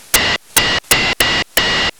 Запись SDRsharp